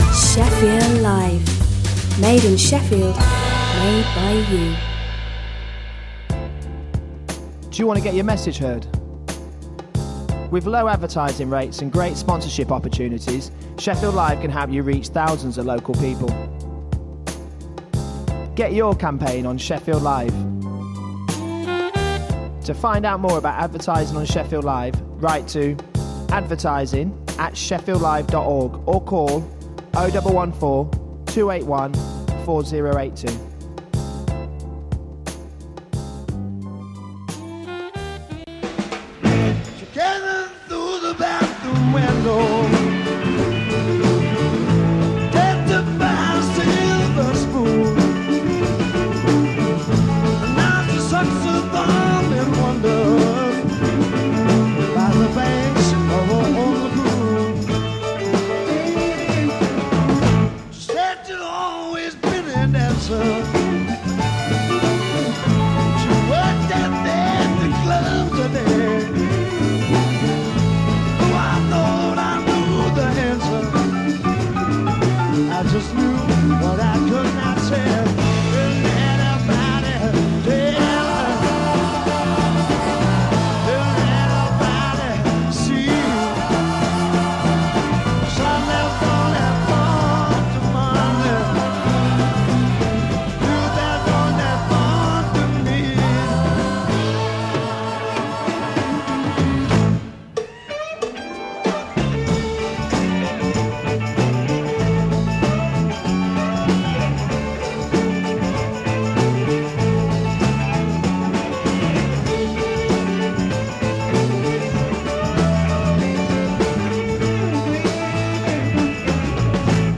Jolly music, news, chat and regular prize giveaways!